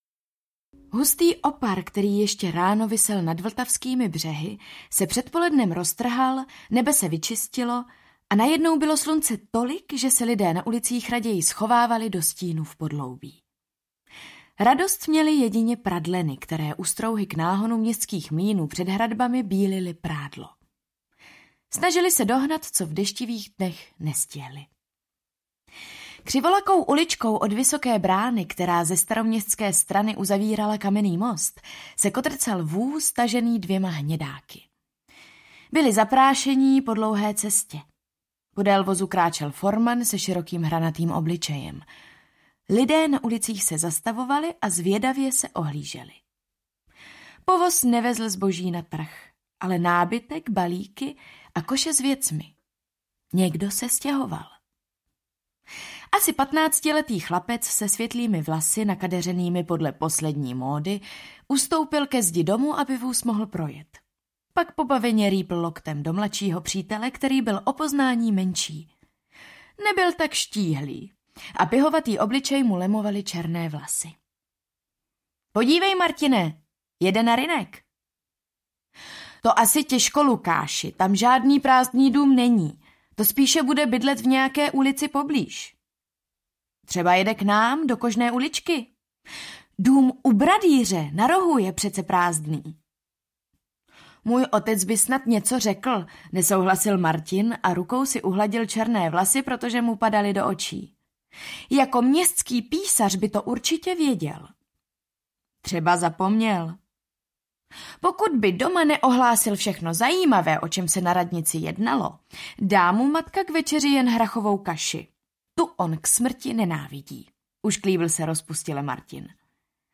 Bratrstvo křišťálu audiokniha
Ukázka z knihy
• InterpretEva Josefíková